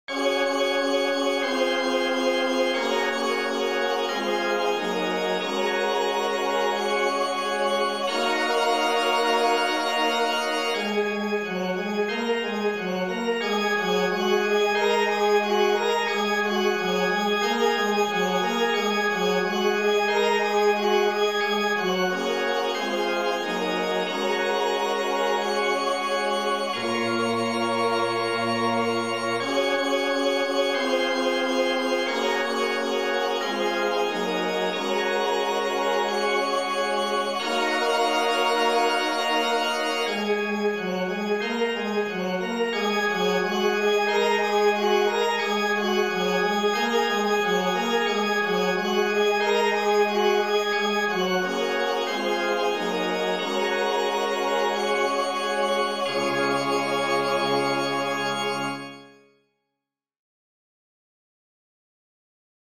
(words not sung)
for SATB Choir and Handbells and Organ